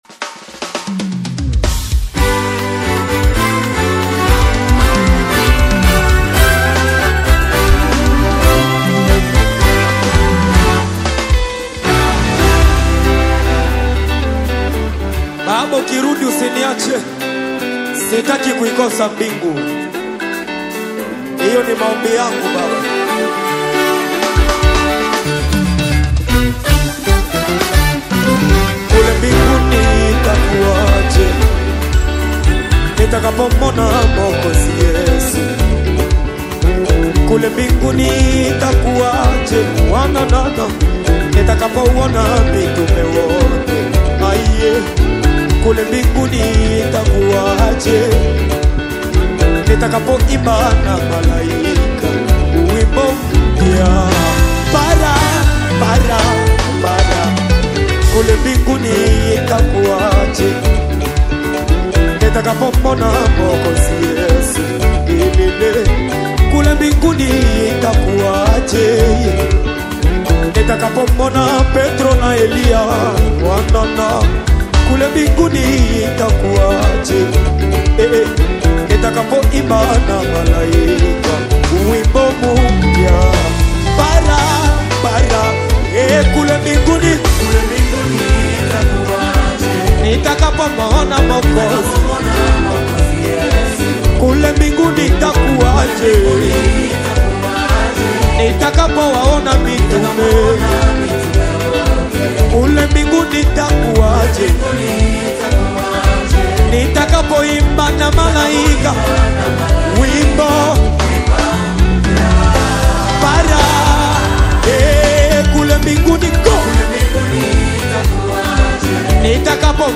The breathtakingly arranged and vocally transcendent single